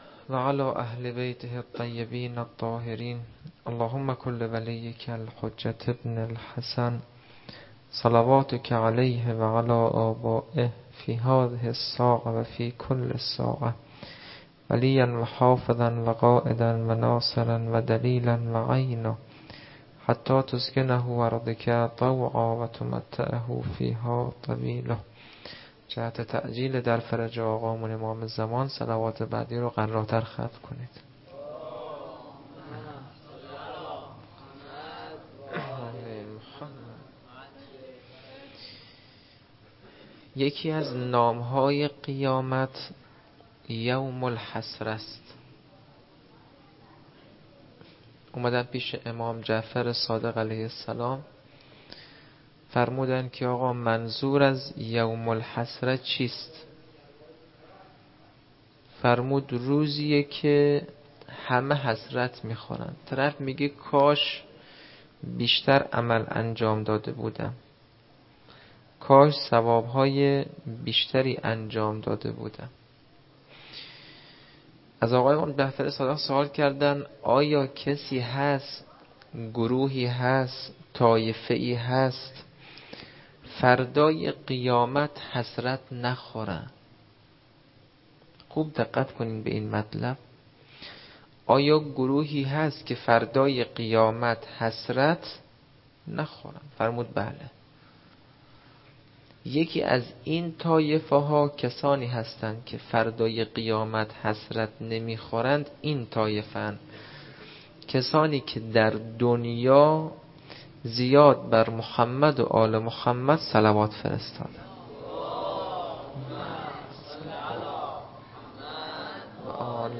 سخنرانی - روضه